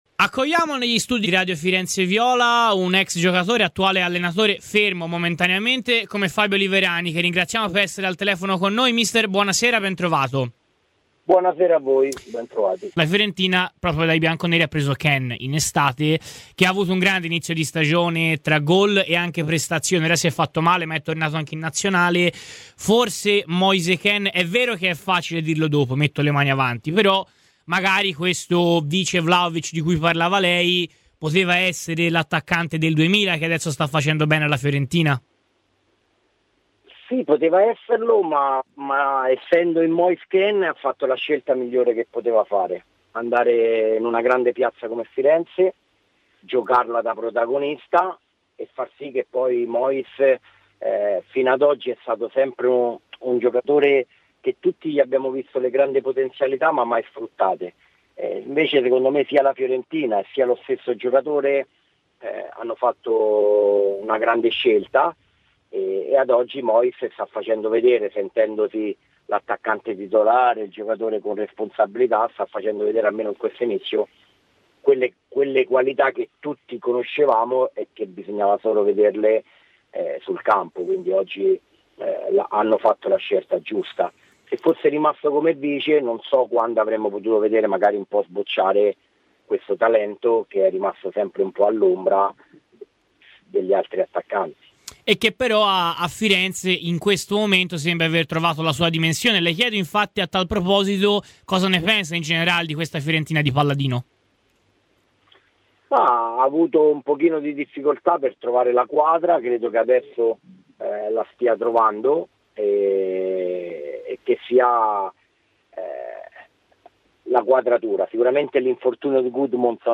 A commentare la stagione della Fiorentina, il giorno dopo la vittoria di San Gallo, è Fabio Liverani, ex centrocampista viola e attuale allenatore, interpellato in esclusiva da Radio FirenzeViola proiettandosi così sull'imminente match con la Roma: "Sarà una gara molto tattica e da guardare, la Fiorentina mentalmente sta meglio ma non può sottovalutare la Roma.